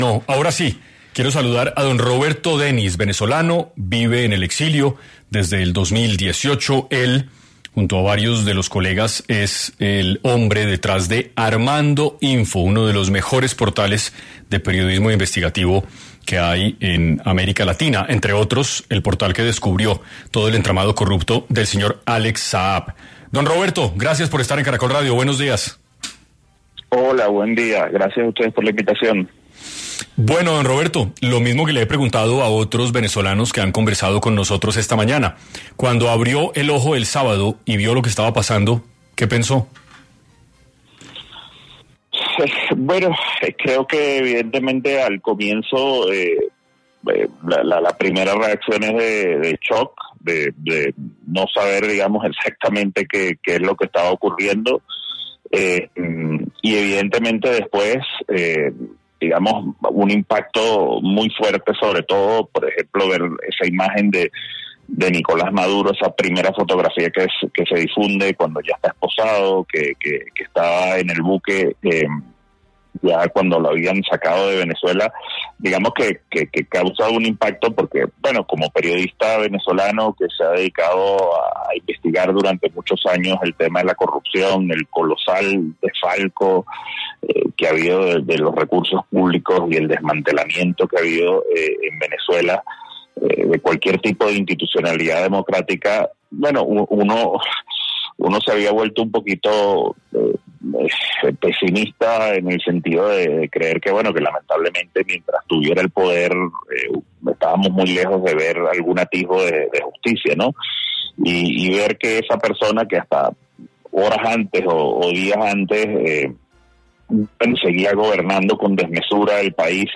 Maduro no está, pero los Cabello y Rodríguez seguirán operando como Alex Saab: Periodista venezolano